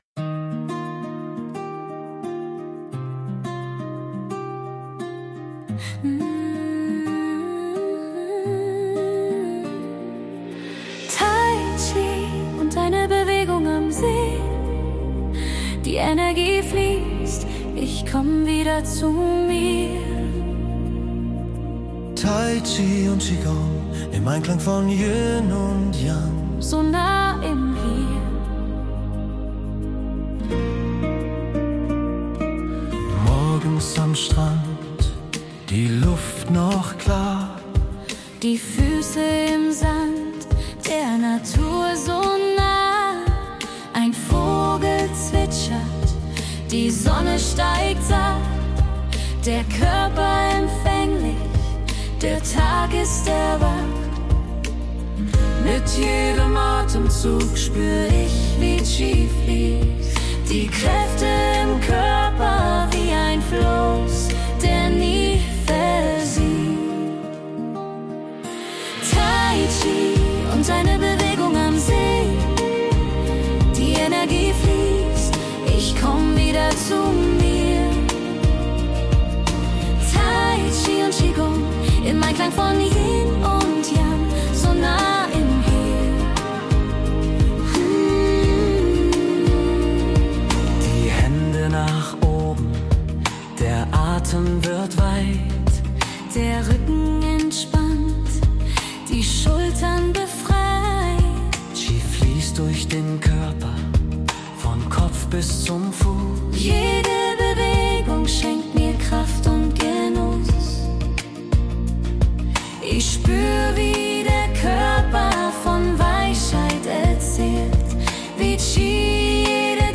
"Musik: GEMA-frei / Komposition mit Suno AI